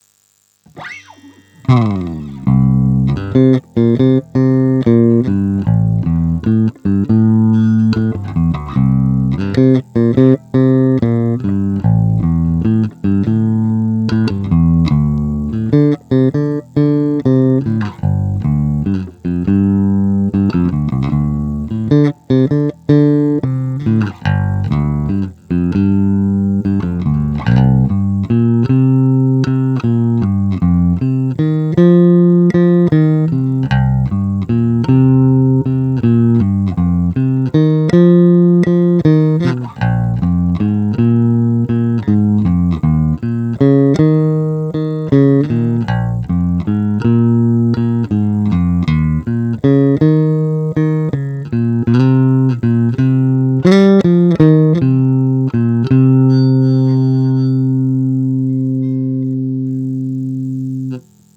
No asi klasický jazz bass J Posuďte sami z nahrávek, které jsou provedeny rovnou do zvukovky bez úprav.
Vše na plno
V ukázkách ti trochu sice něco "cvrčí" v pozadí a občas přebuzuje, ale tvoje nahrávky se mi líbí víc než originál z toho videa, kde mi to připadá nahrané dost nechutně s "mid scooped" charakterem (schválně si to poslechněte a porovnejte).